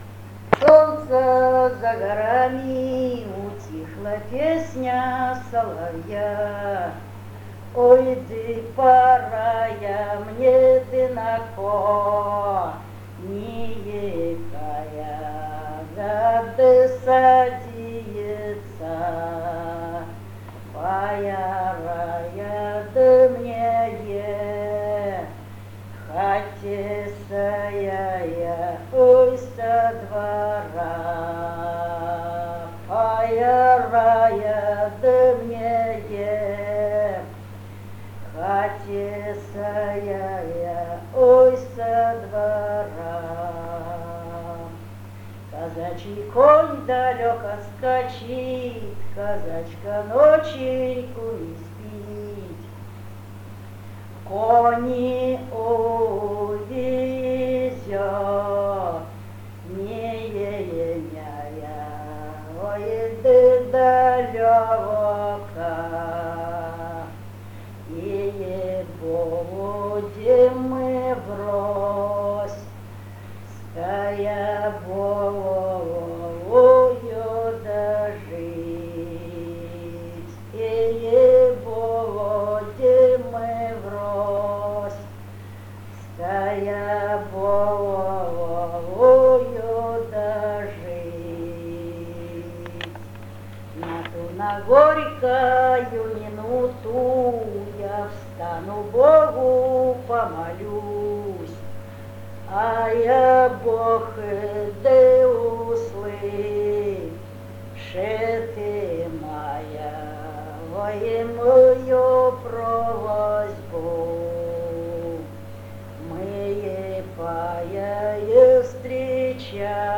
Лирическая песня